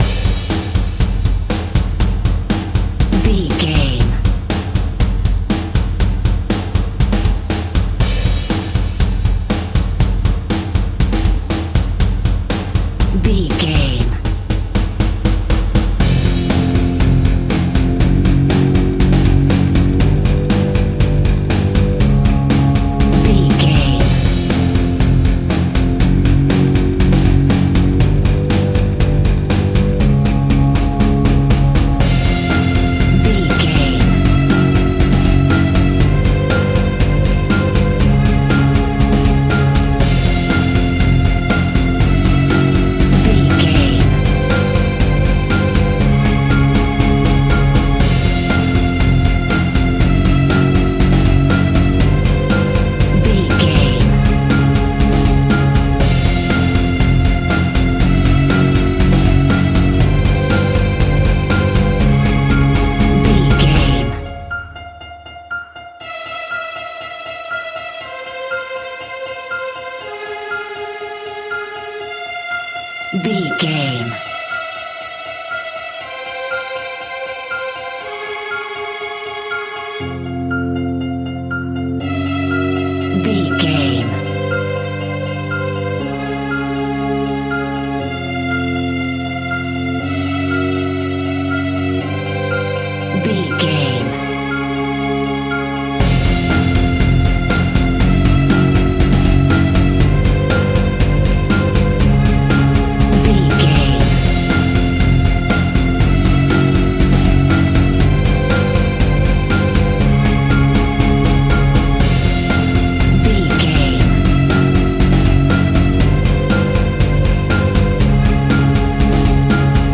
Halloween Crime Music.
In-crescendo
Thriller
Aeolian/Minor
scary
tension
ominous
dark
suspense
eerie
driving
energetic
drums
bass guitar
piano
strings
synth
pads